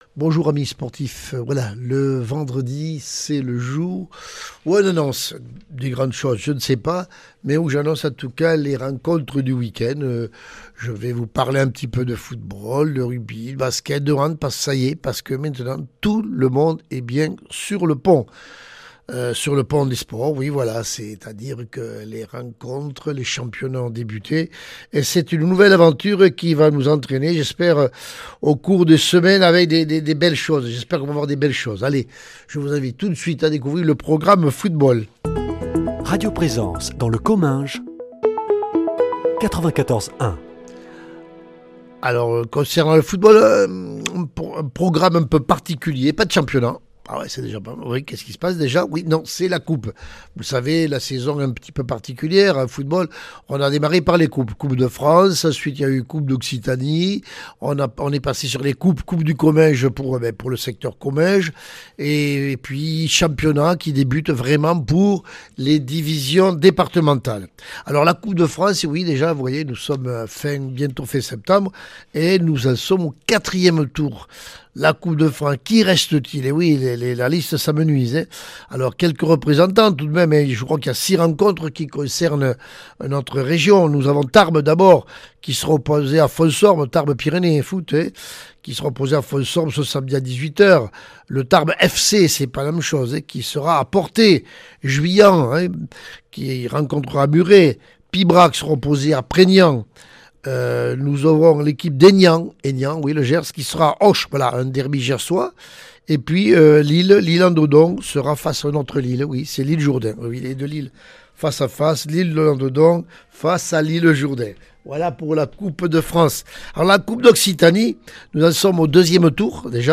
Interview et reportage du 26 sept.